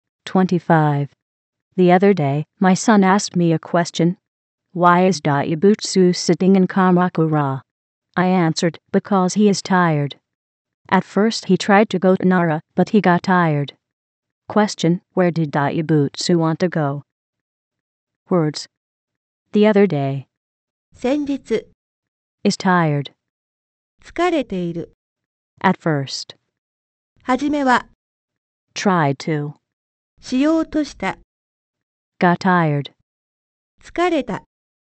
◇音声は日本語、英語ともに高音質のスピーチエンジンを組み込んだ音声ソフトを使って編集してあります。
音声−普通